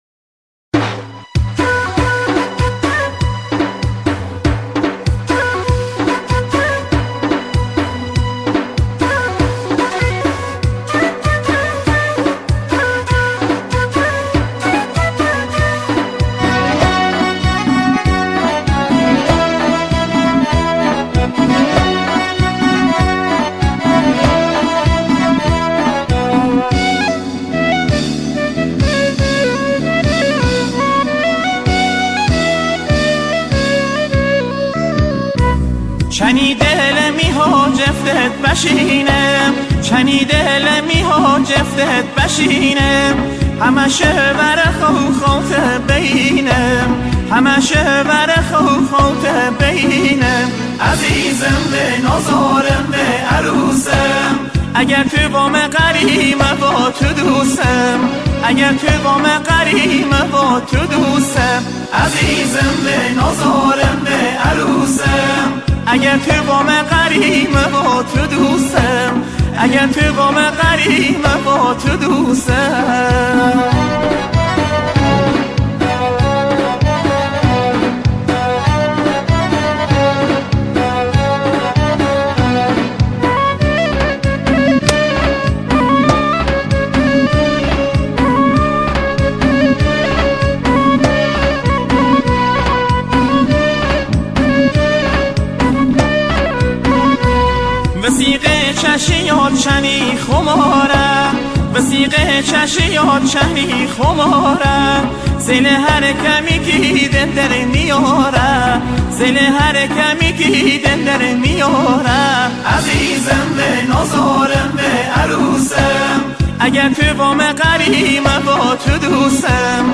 آهنگ لری شاد